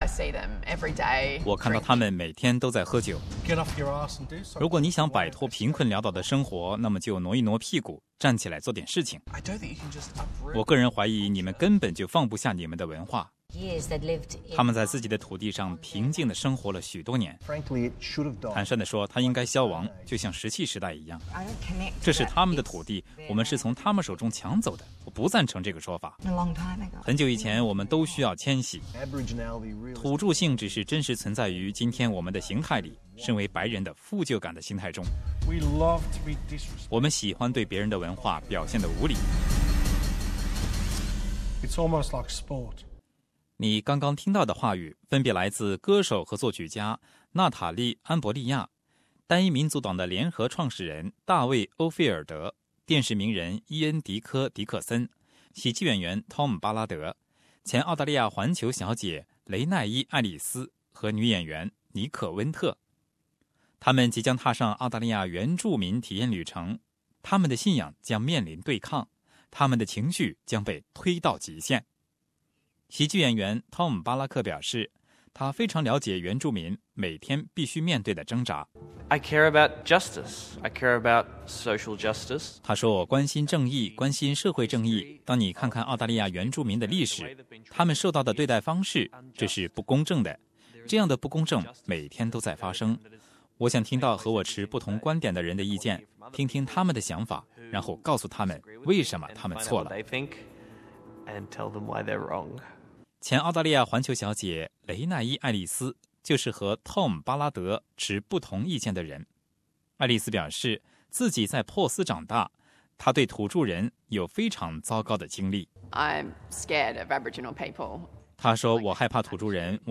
以下故事可能包含已经去世的人的声音和名字。